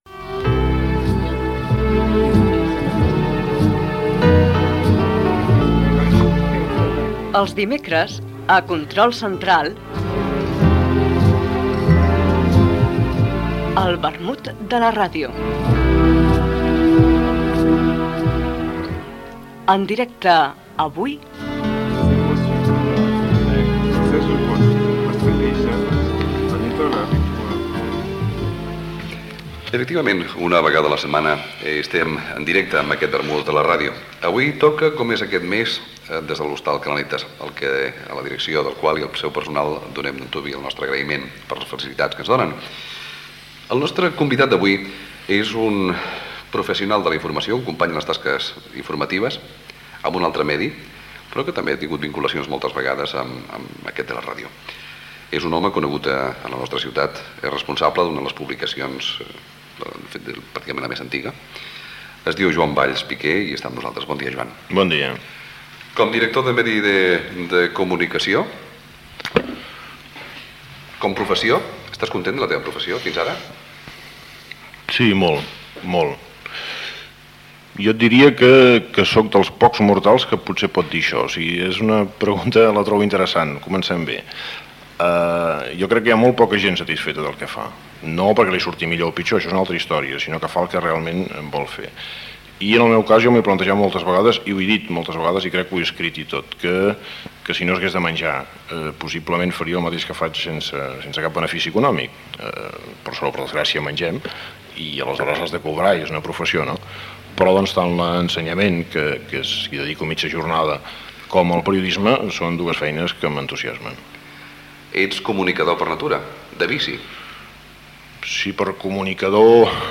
Espai fet en directe des de l'Hostal Canaletas d'Igualda.
Entreteniment